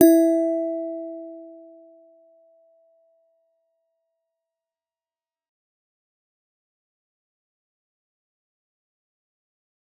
G_Musicbox-E4-f.wav